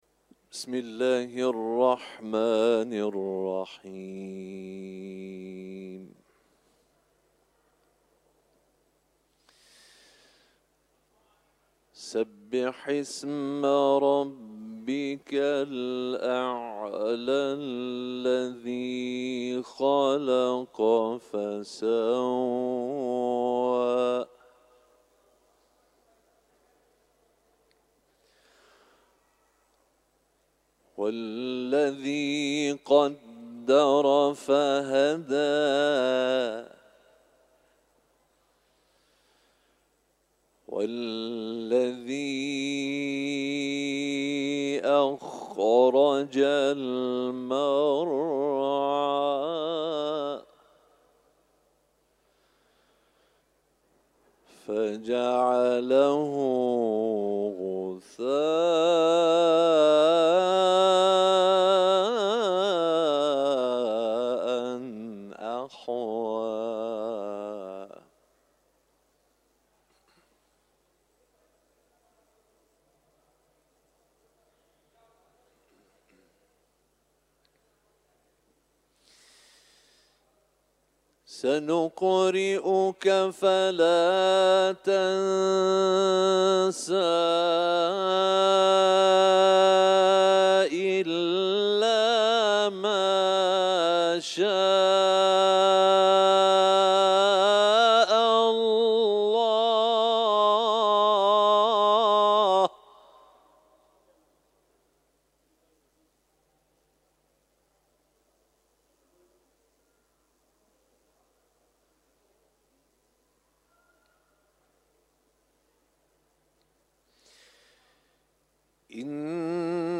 تلاوت قرآن